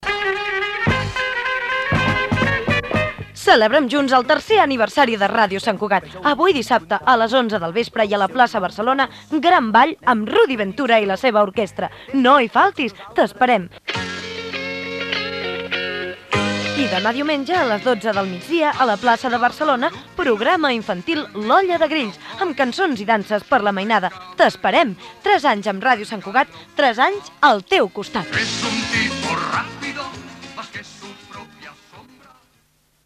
Comercial